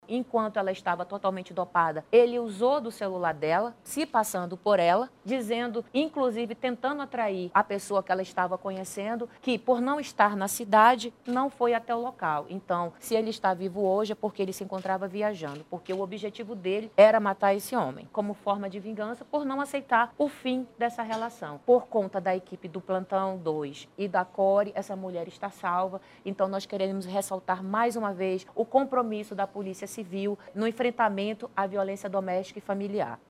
Em coletiva de imprensa nessa segunda-feira